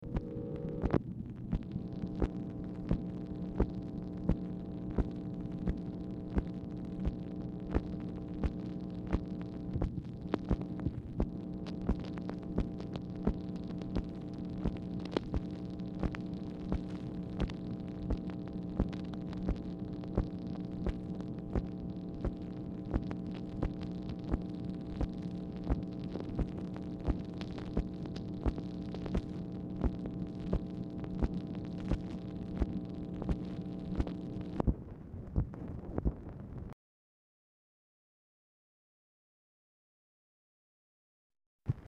Telephone conversation # 7613, sound recording, MACHINE NOISE, 5/8/1965, time unknown | Discover LBJ
Telephone conversation
Format Dictation belt